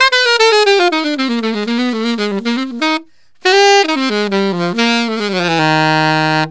Alto One Shot in Eb 01.wav